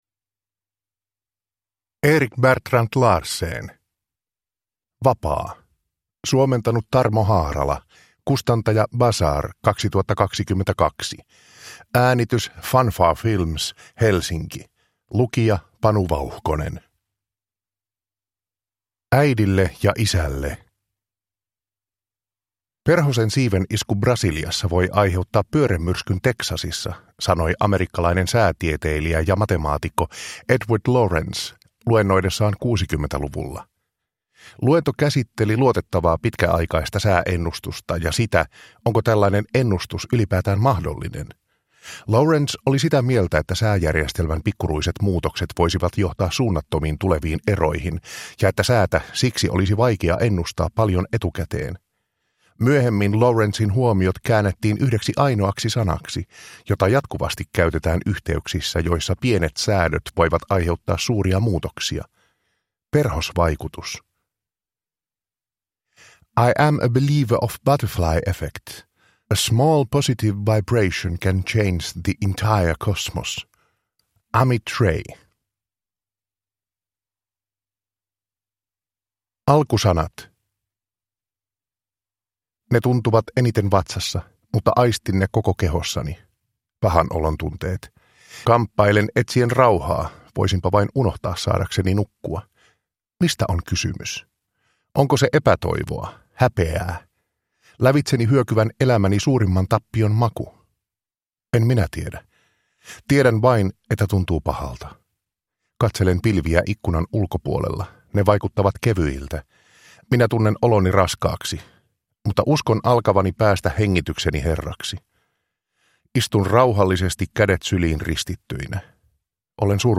Vapaa – Ljudbok